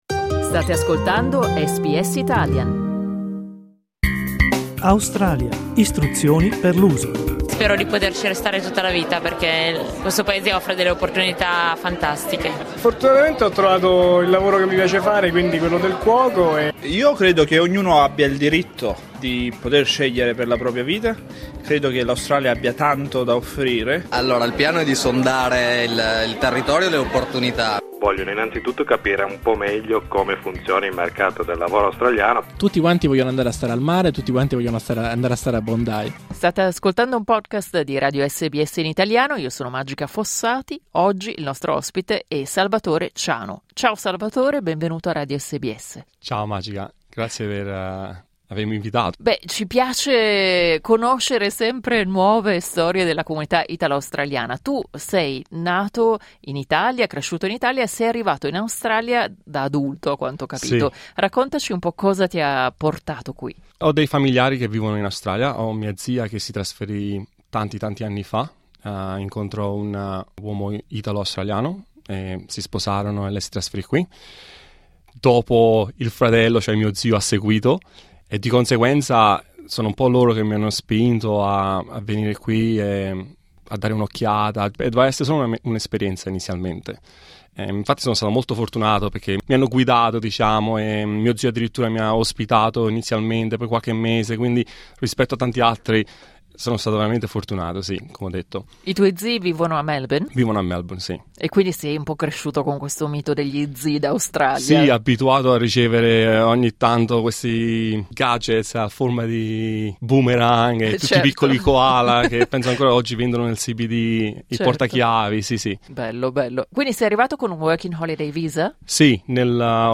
Clicca sul tasto "play" in alto per ascoltare l'intervista Dopo essersi stabilito in Australia grazie ad una sponsorizzazione